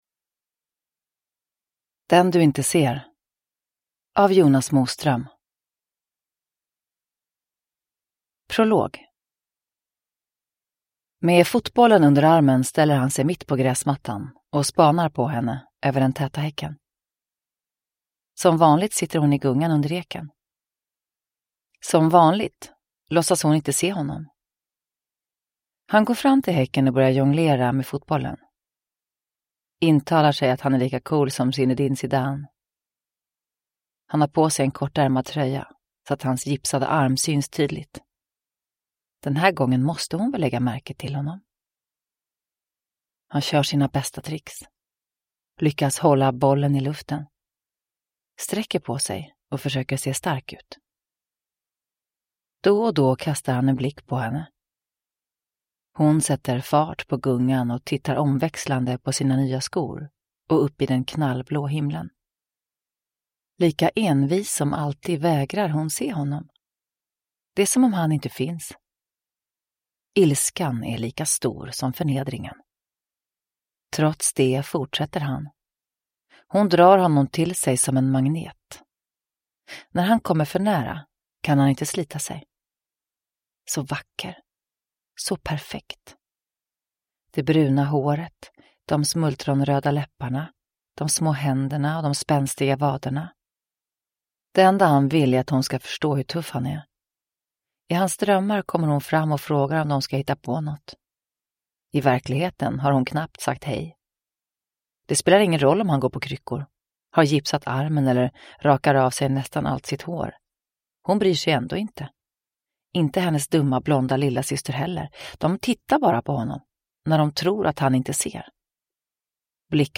Den du inte ser – Ljudbok
Uppläsare: Eva Röse